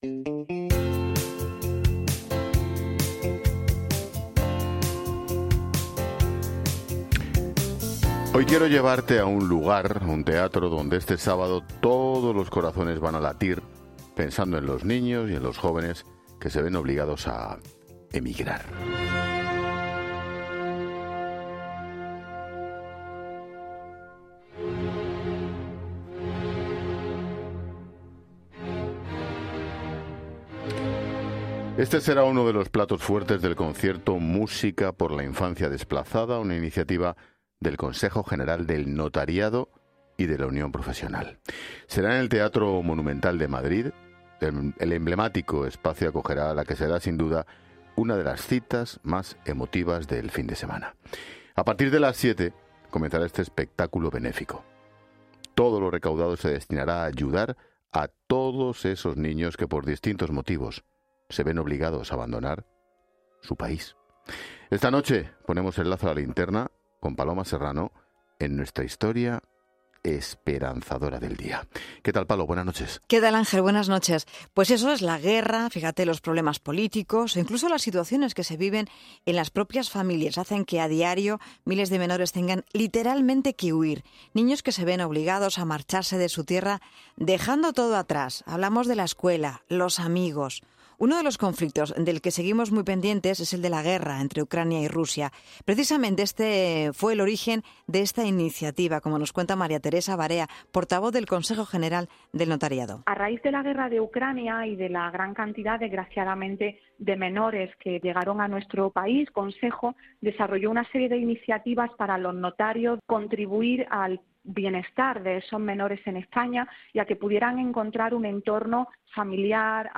Puedes escuchar el reportaje de Ángel Expósito sobre el concierto benéfico “Música por la infancia desplazada”, organizado por el Consejo General del Notariado y Unión Profesional, para apoyar a los niños migrantes que llegan a España. El concierto tendrá lugar este sábado 6 de abril a las 19 horas en el Teatro Monumental de Madrid (C/ Atocha, 65). Todos los beneficios obtenidos irán destinados a Cruz Roja Española y a Plataforma de Infancia.